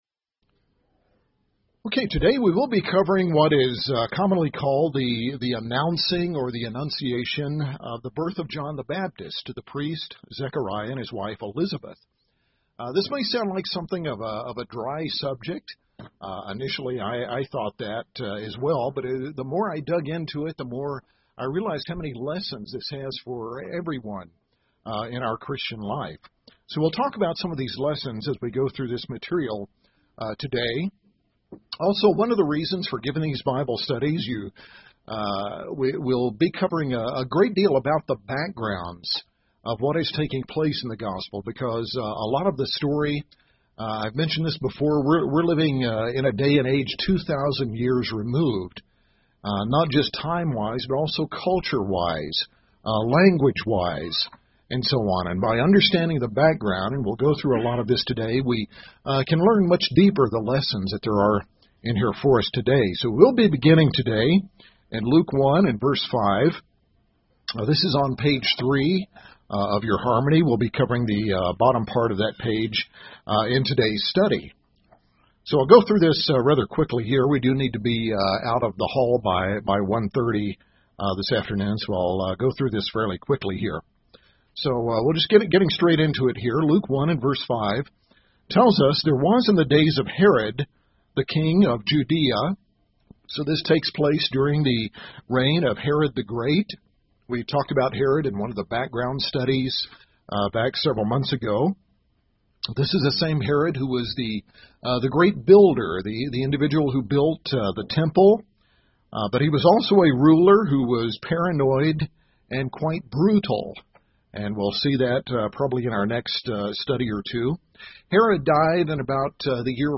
In this class, we cover the context and culture of their times and the lessons we should learn from their remarkable story as they are told they will become the parents of a great prophet—the one who will prepare the way for the coming of the Messiah.